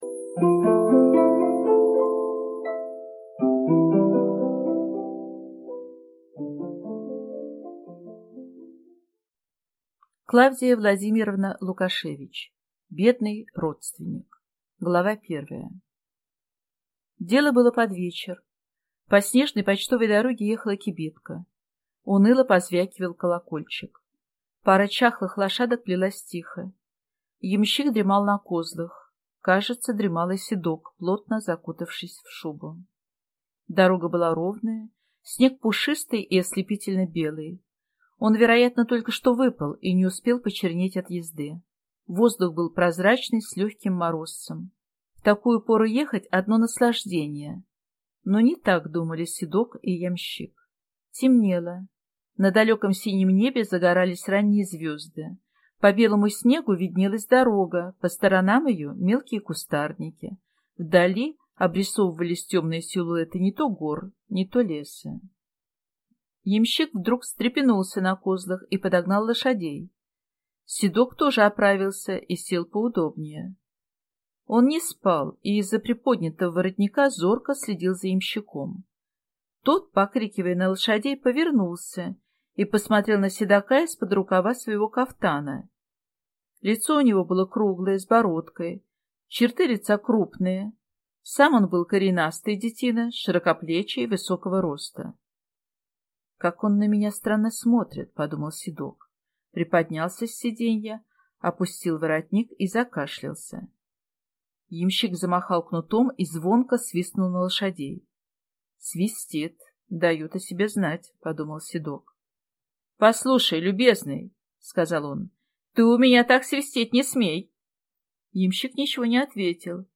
Аудиокнига Бедный родственник | Библиотека аудиокниг